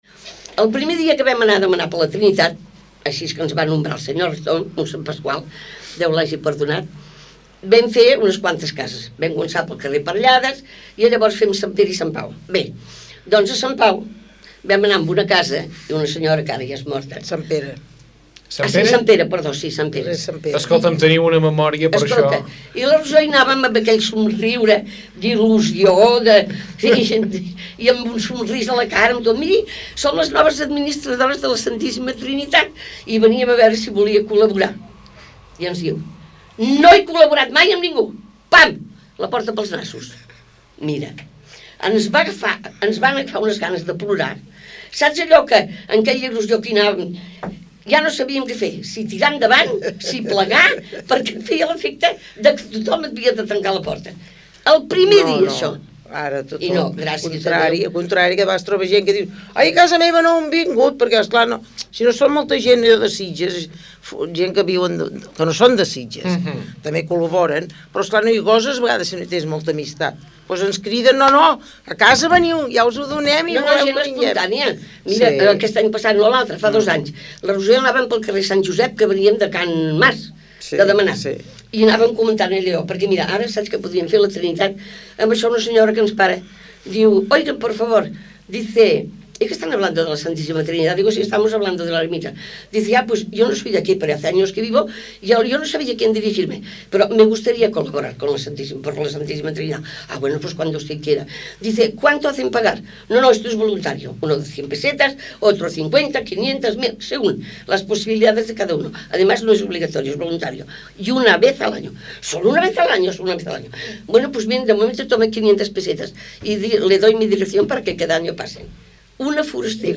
Una conversa per recordar.